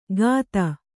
♪ gāta